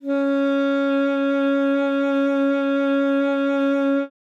42e-sax05-c#4.wav